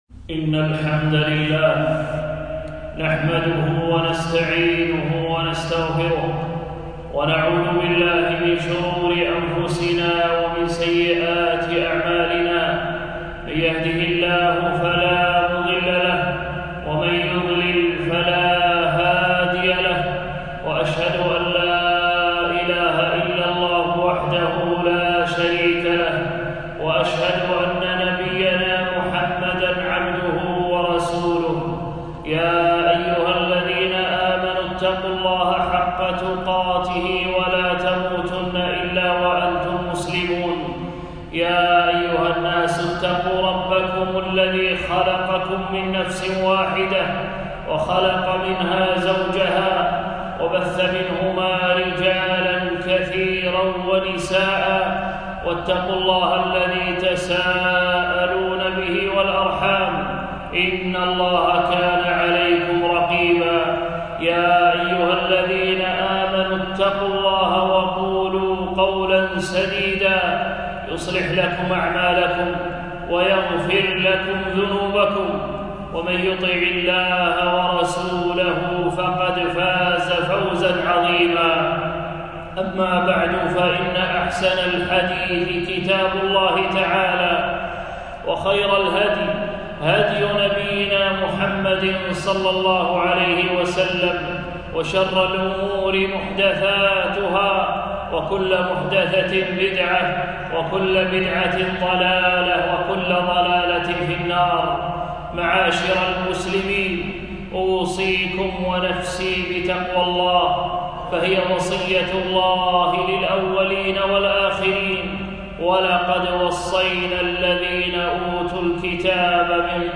خطبة - فضائل العشر من ذي الحجة 1439هــ